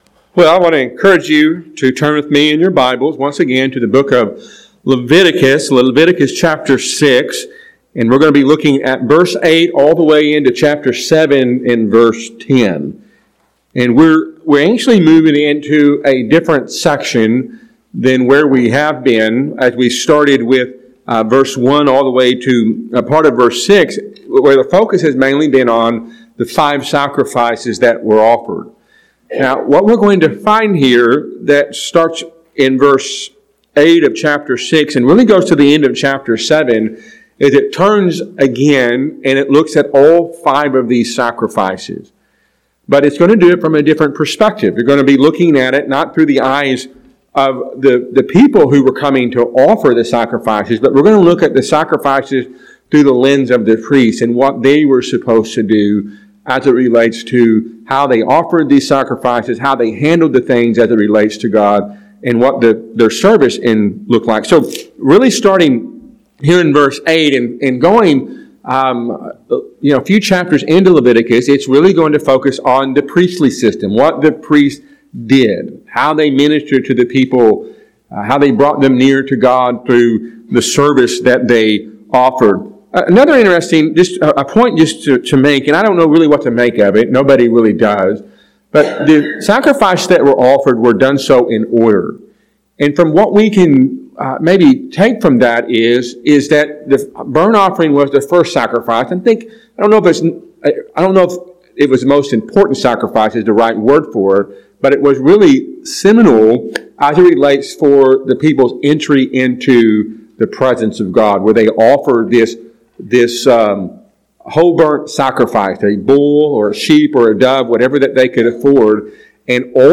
A sermon from Leviticus 6:8-7:10.